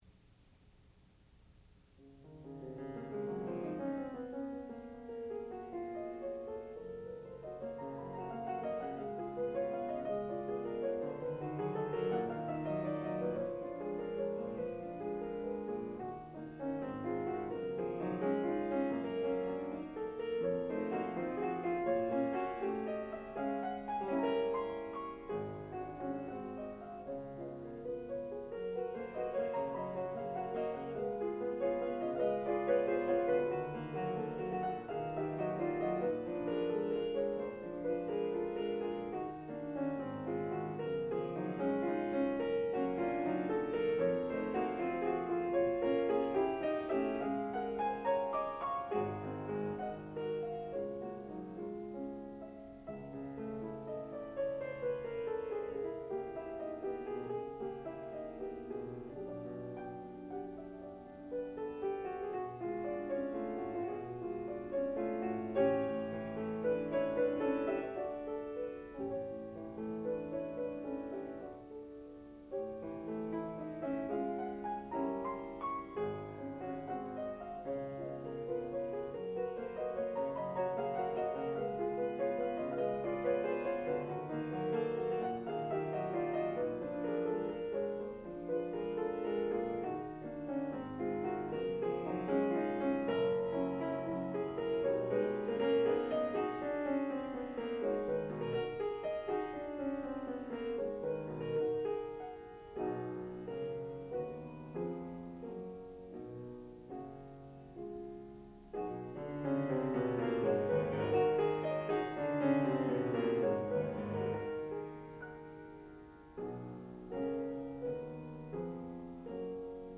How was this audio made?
Student Recital